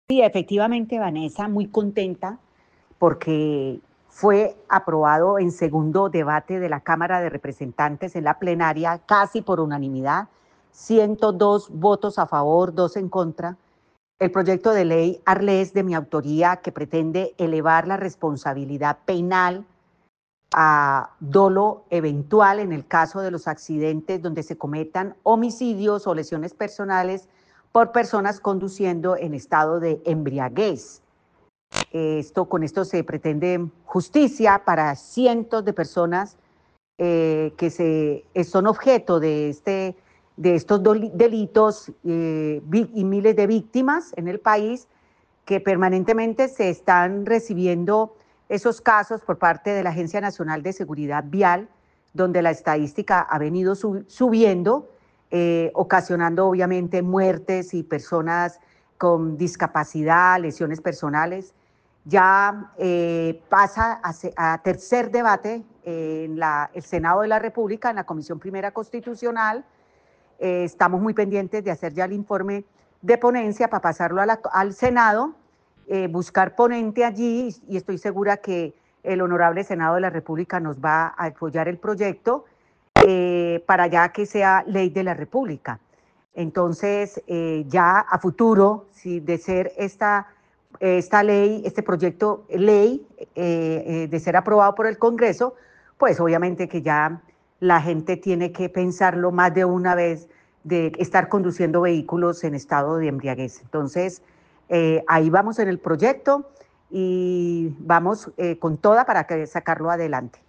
Representante a la Cámara, Piedad Correal